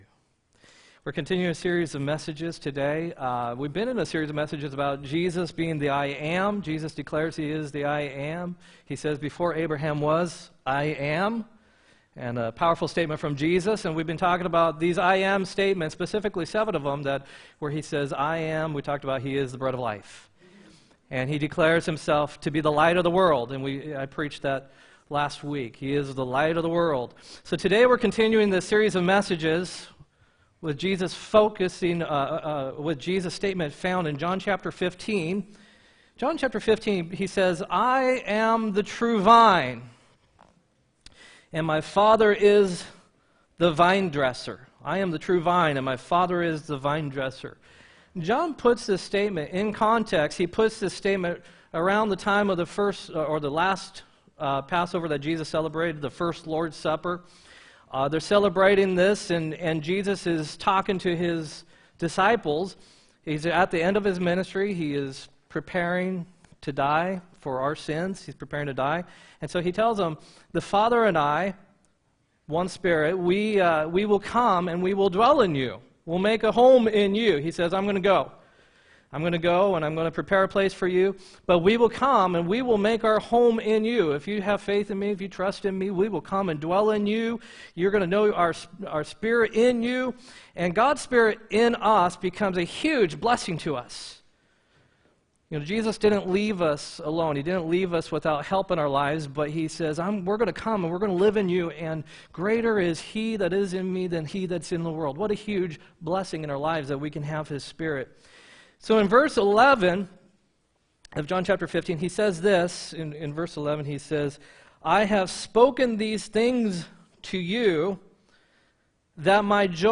10-28-17 sermon